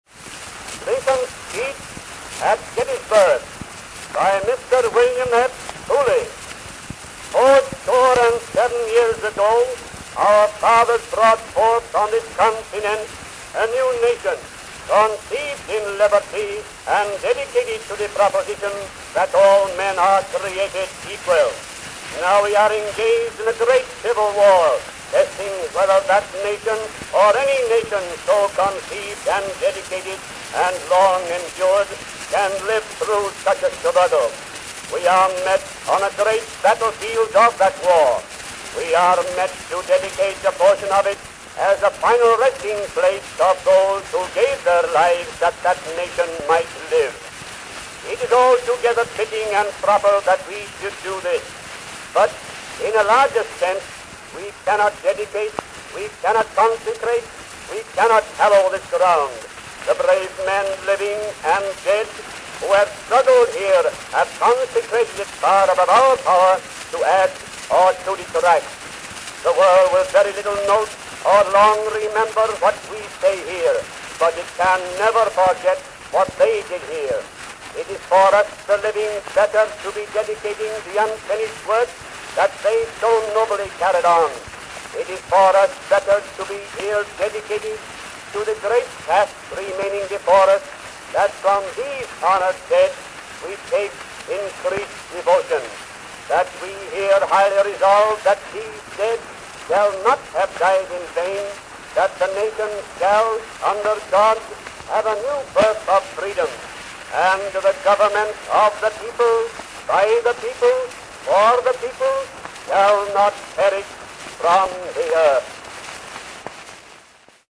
Back to GED-GEN Sample Gallery Page Header Abraham Lincoln Gallery Prior (12 / 16) Next Gallery Lincoln's speech at Gettysburg [sound recording] Download Gettysburg address.